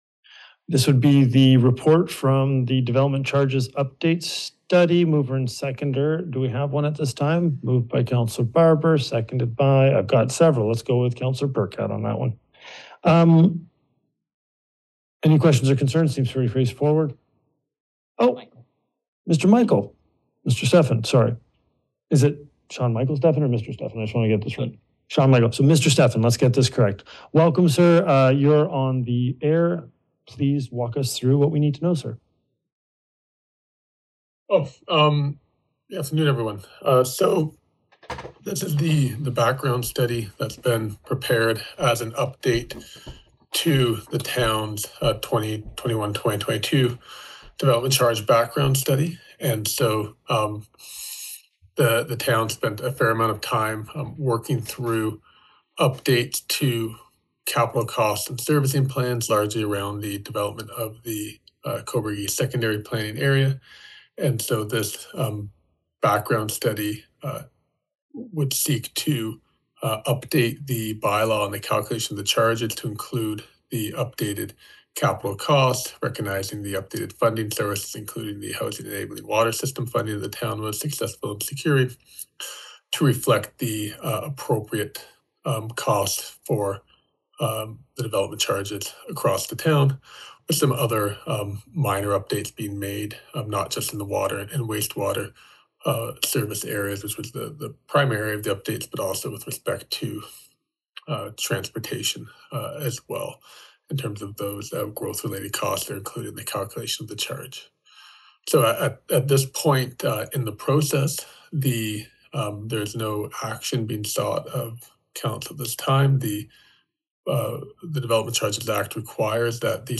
A written and verbal report was given at the meeting, including the potential for mandatory deferral of residential development charges. Questions were also raised about existing development charge reserve funds and external financing costs being factored into the new charges.